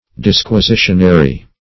Search Result for " disquisitionary" : The Collaborative International Dictionary of English v.0.48: Disquisitionary \Dis`qui*si"tion*a*ry\, a. Pertaining to disquisition; disquisitional.